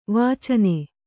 ♪ vācane